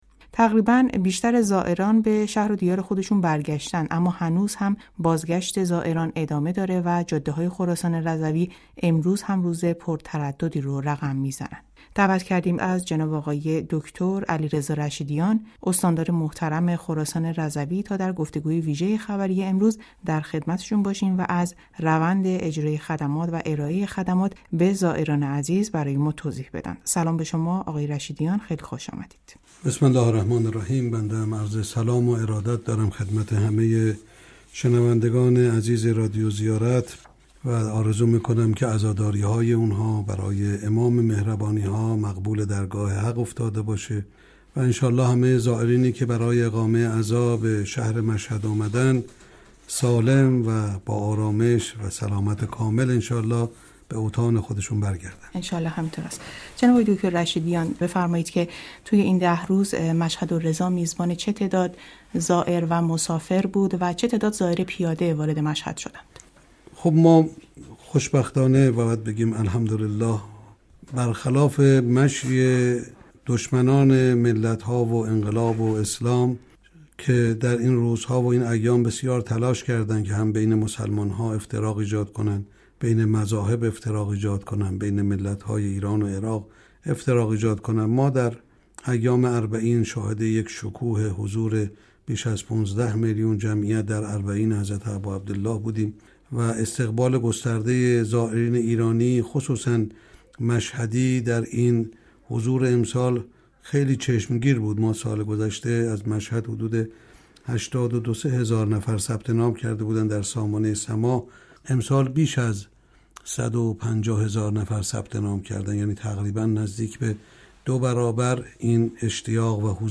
علیرضا رشیدیان استاندار خراسان رضوی با حضور در استودیوی رادیو زیارت به گزارشی از ارائه خدمات به زائران در دهه آخر صفر پرداخت و به سوالات پاسخ داد